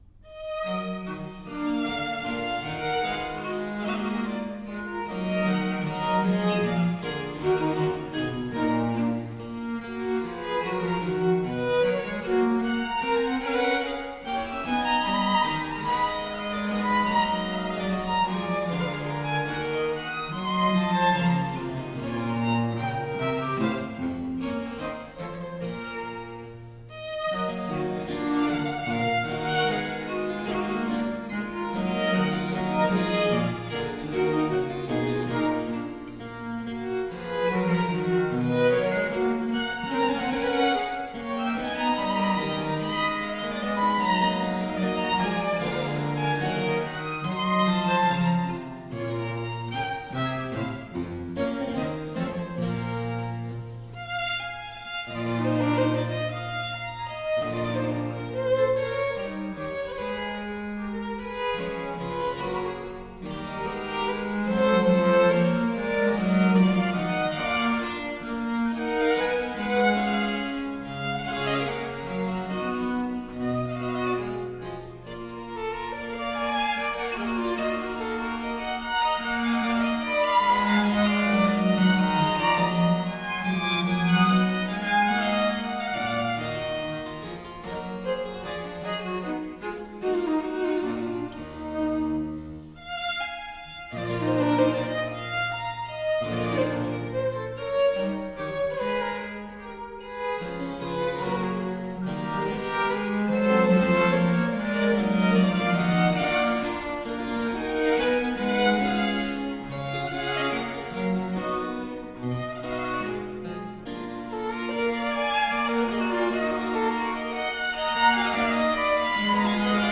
Soundbite 4th Movt
for 2 Violins and Cello
The finale is simply marked Non presto.